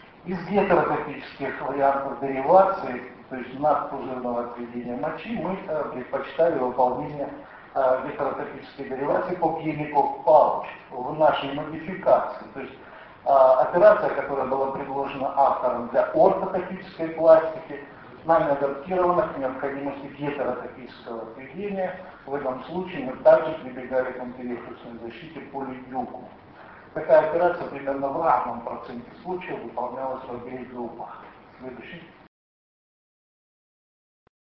Съезд Ассоциации Урологов Дона с международным участием. Ростов-на-Дону, 27-28 октября 2004 года.
Лекция: "Цистэктомия и деривация мочи у пожилых пациентов при раке мочевого пузыря: эффективность и безопасность".